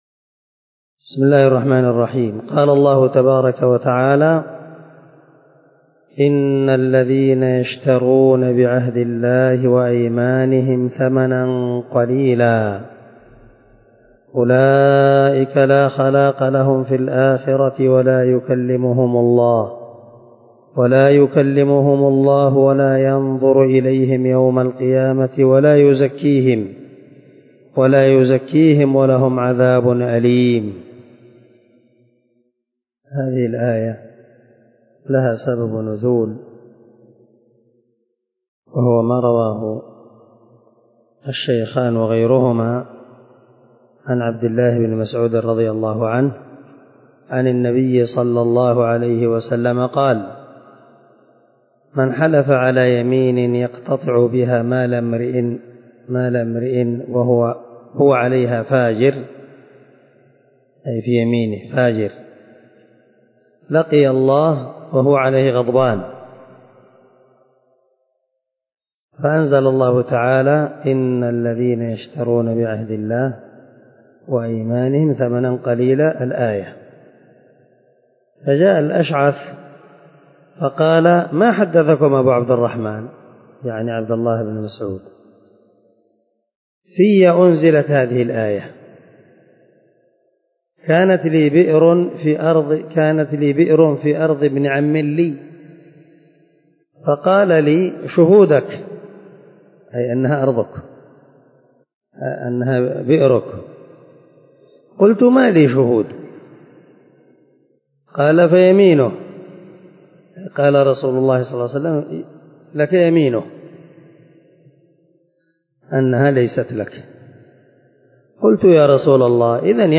178الدرس 23 تفسير آية ( 77 – 78 ) من سورة آل عمران من تفسير القران الكريم مع قراءة لتفسير السعدي